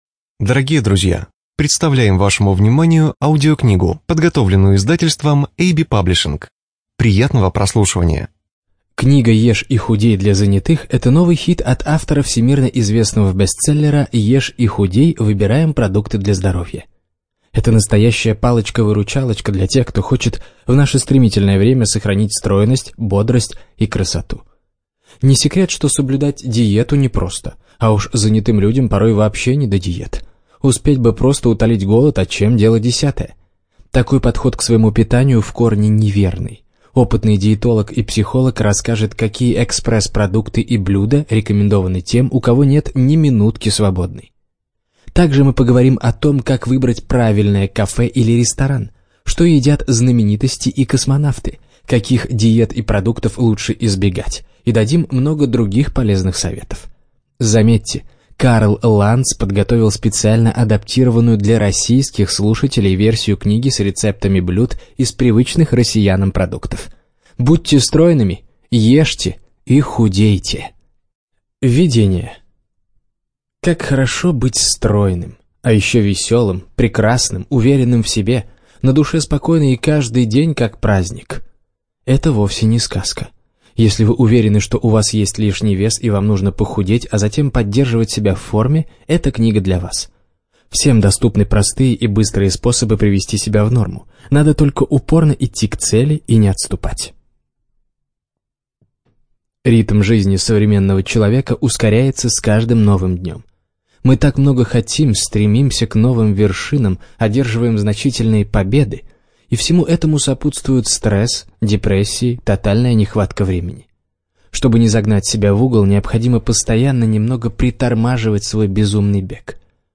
Студия звукозаписиAB-Паблишинг